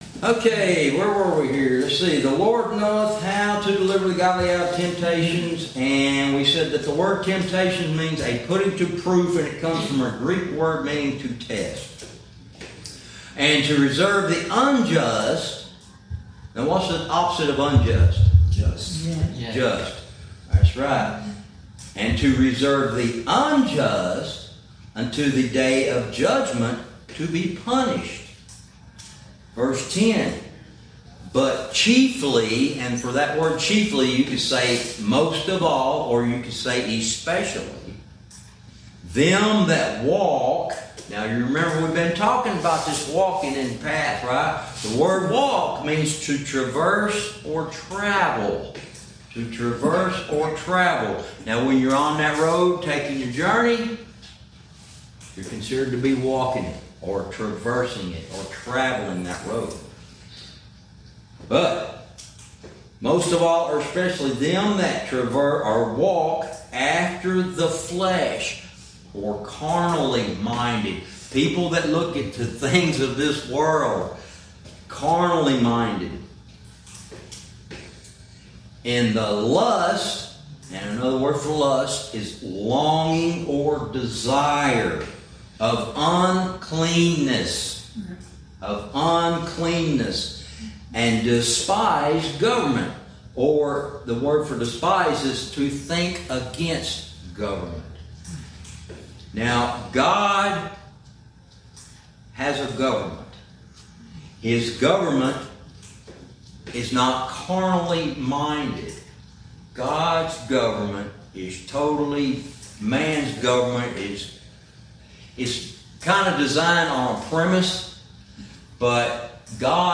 Verse by verse teaching - Lesson 40